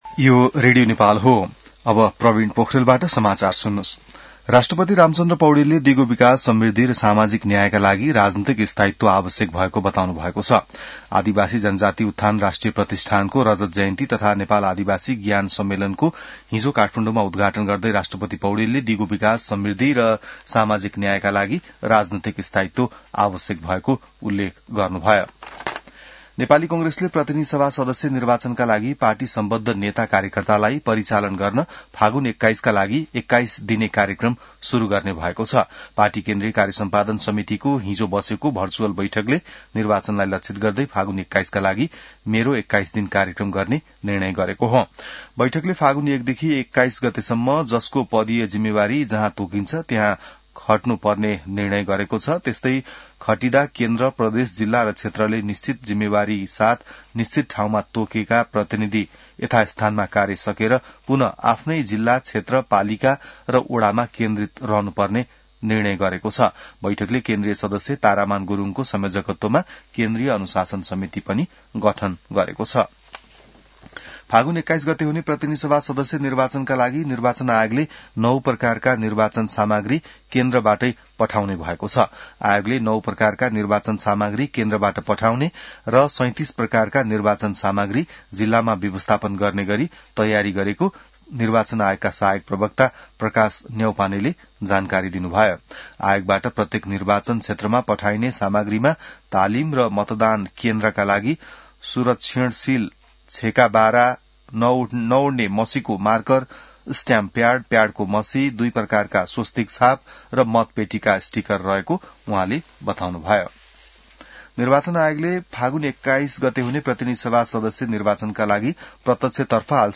बिहान ६ बजेको नेपाली समाचार : २६ माघ , २०८२